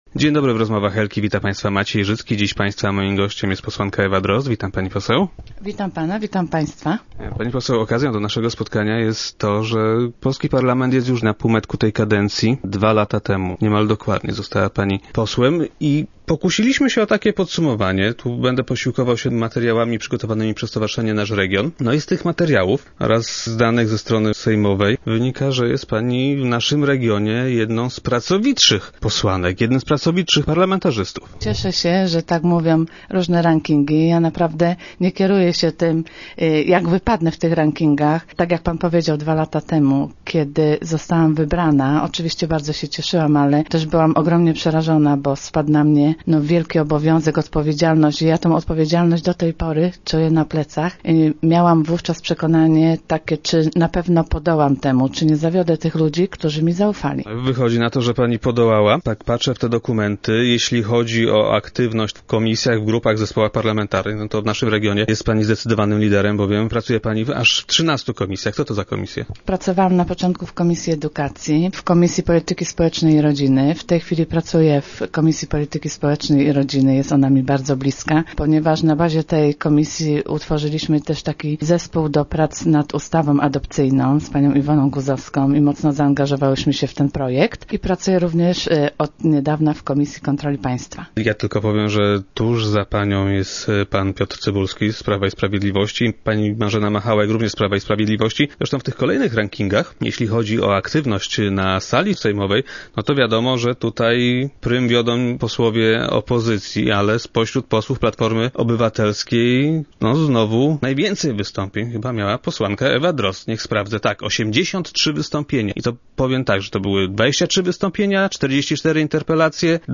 Jak powiedziała w Rozmowach Eli posłanka Ewa Drozd, był to dla niej czas wytężonej pracy.
Ostatnio zadawałam pytanie w sprawie zamiejscowych ośrodków egzaminacyjnych, w których mogłyby odbywać się egzaminy na prawo jazdy - mówiła posłanka na radiowej antenie.